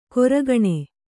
♪ koragaṇe